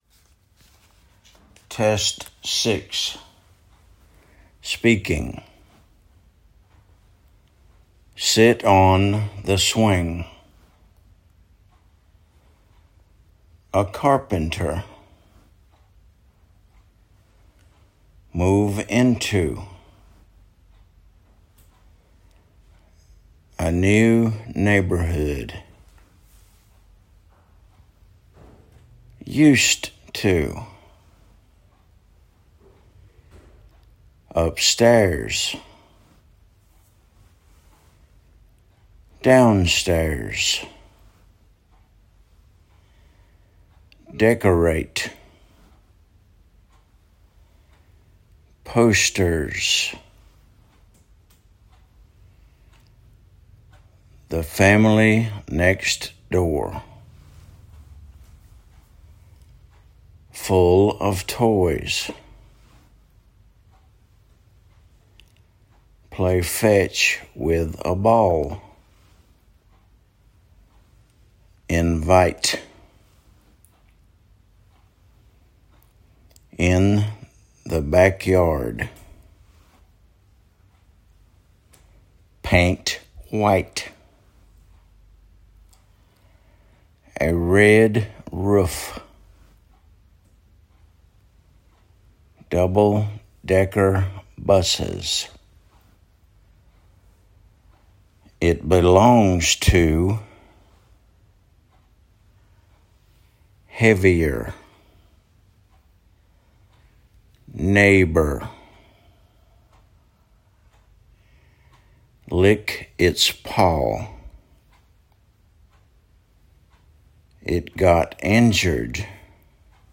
sit on the swing /sɪt ɒn ðə swɪŋ/
play fetch with a ball /pleɪ fɛʧ wɪð ə bɔːl/
the room is cozy /ðə ruːm ɪz ˈkəʊzi/